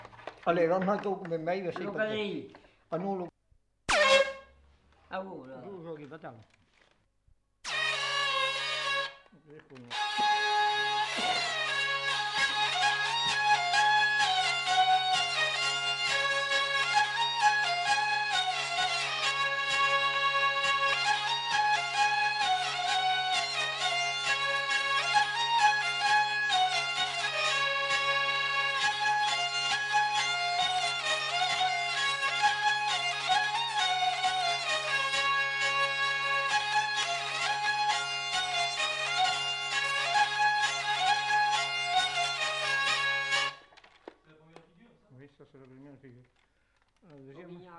Lieu : Vielle-Soubiran
Genre : morceau instrumental
Instrument de musique : vielle à roue
Danse : quadrille